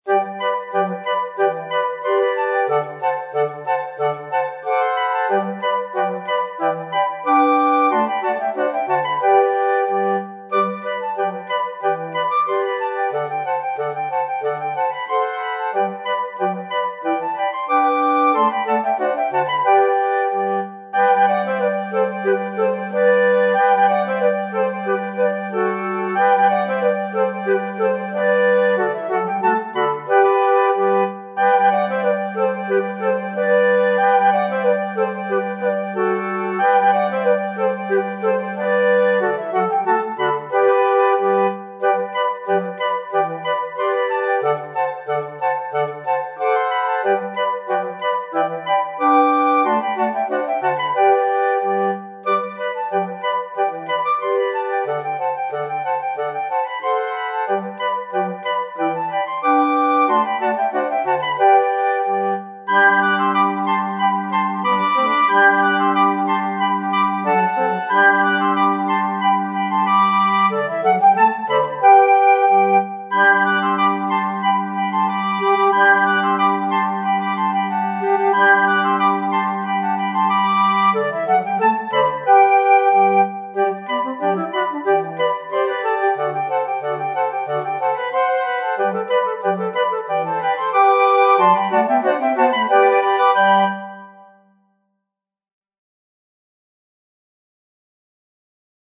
Musik: traditionell
in einem leichten und gefälligem Arrangement
• C-Besetzung: Flöte 1/2/3, Altflöte in G, Bassflöte